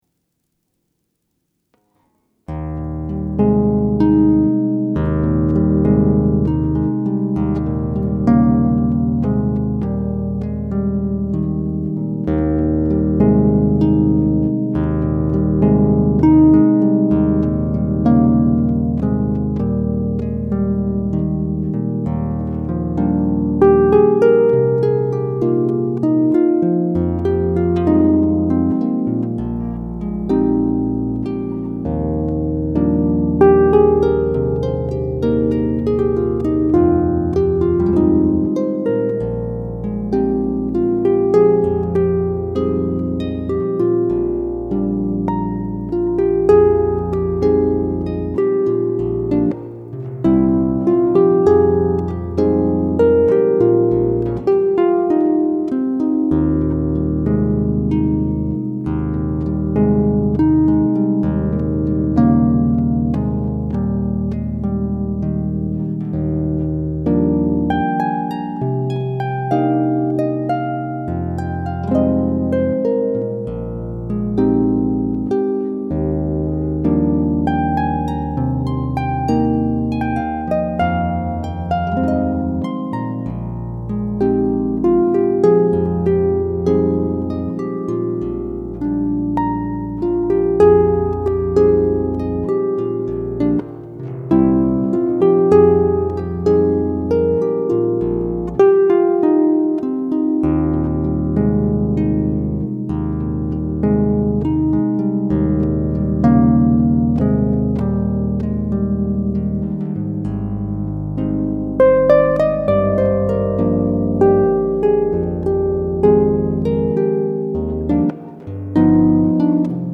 pedal harp
Easy listening and not difficult to play.